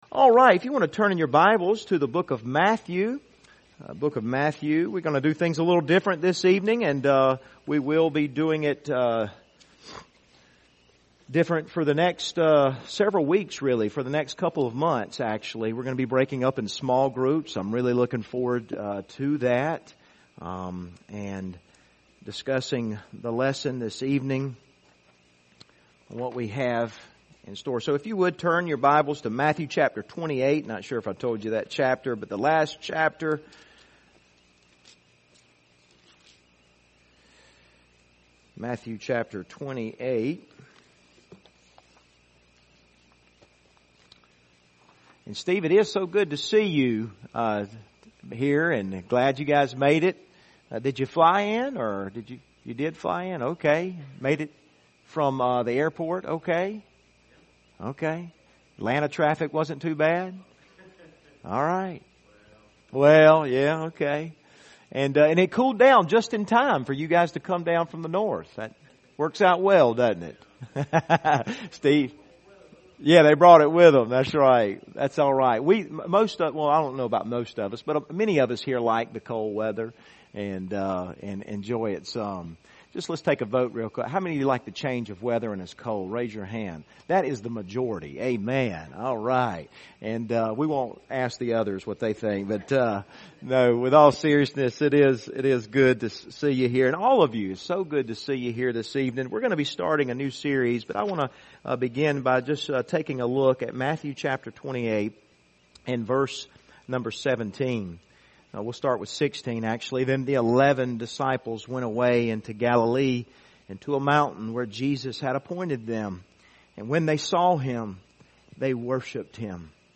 Passage: Matthew 28:16-20 Service Type: Wednesday Evening